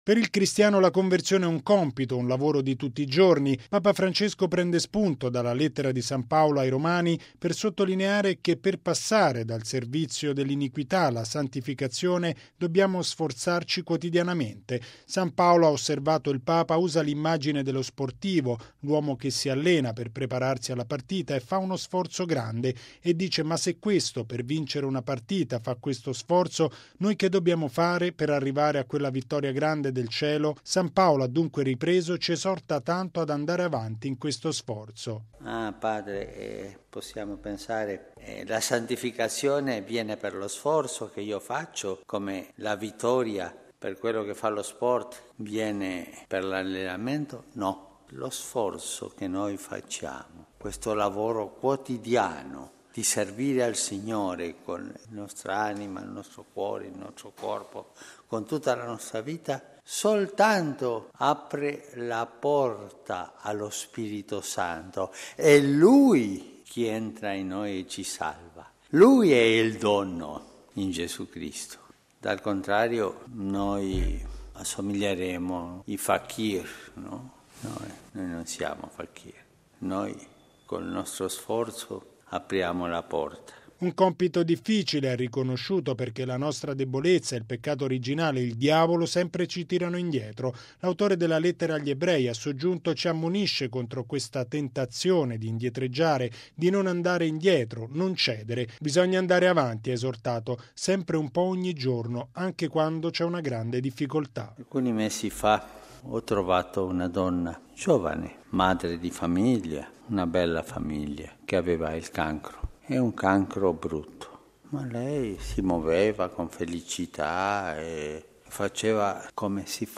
Lo sforzo del cristiano è teso ad aprire la porta del cuore allo Spirito Santo. E’ quanto affermato da Papa Francesco nella Messa mattutina a Casa Santa Marta.